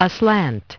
Transcription and pronunciation of the word "aslant" in British and American variants.